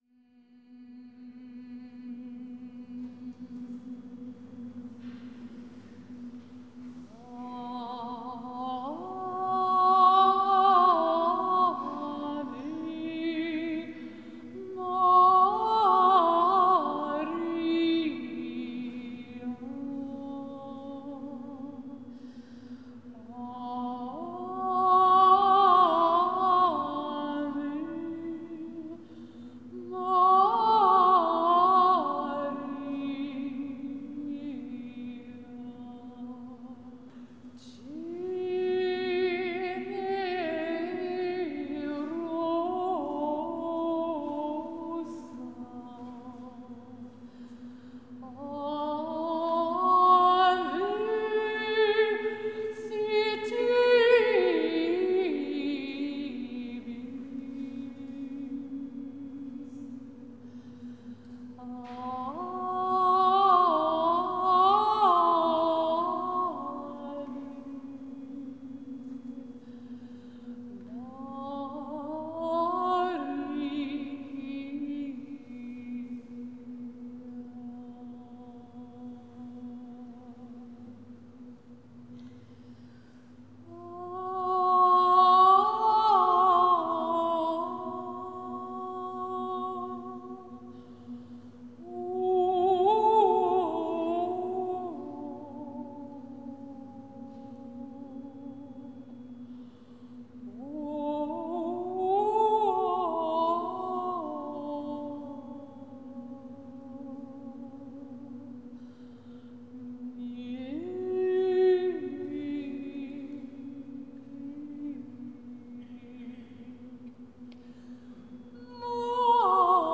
from a live Meditation Concert
The music facilitates a meditative state where habitual thoughts can be halted, and deep emotions can be felt.